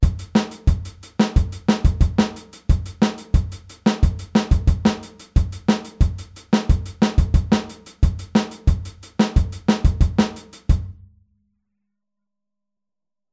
Groove Nr. 16 → Aufteilung linke und rechte Hand auf HiHat und Snare - Musikschule »allégro«
Aufteilung linke und rechte Hand auf HiHat und Snare